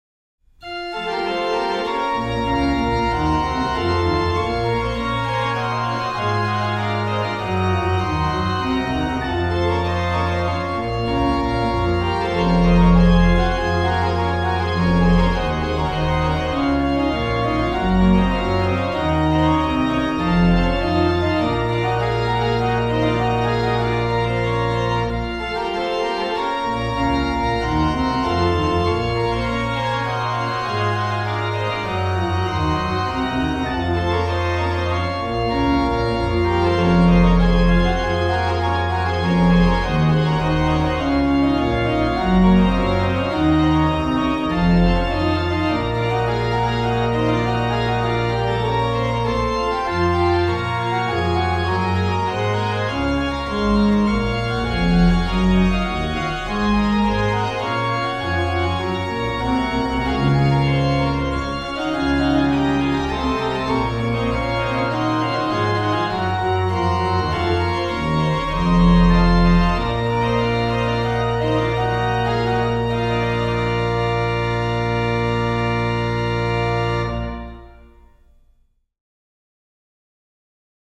Registration   POS: Lged8, Pr4, Oct2, Mix
PED: Sub16, Oct8, Oct4, Tr8